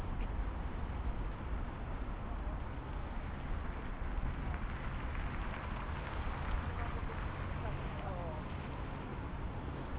noise.wav